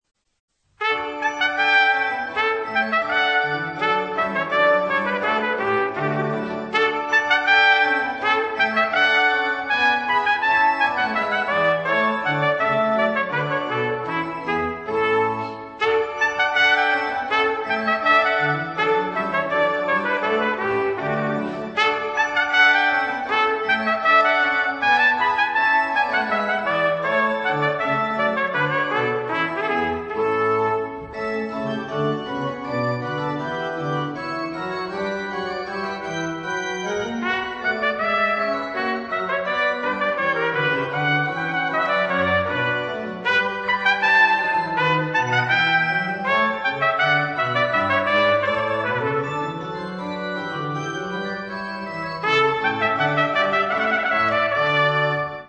pour trompette et orgue